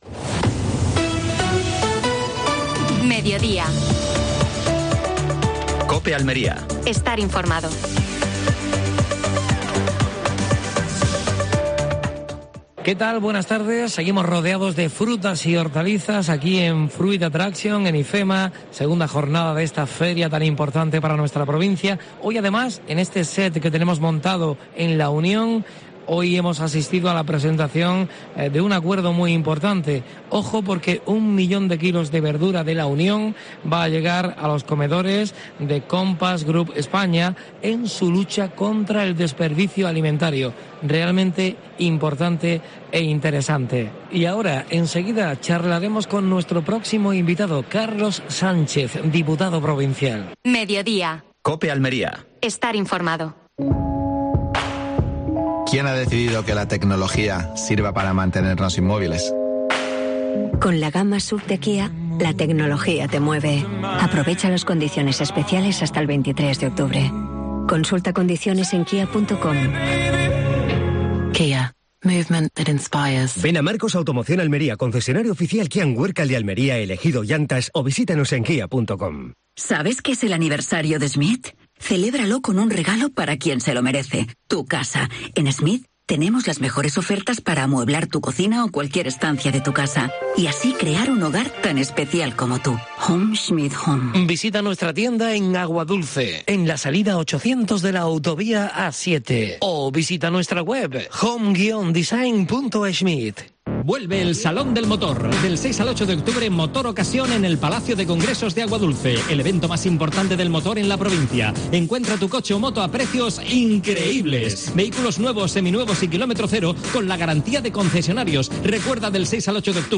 Programa especial desde Fruit Attraction (Madrid). Entrevista a Carlos Sánchez (diputado provincial).